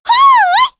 Hanus-Vox_Attack3_kr.wav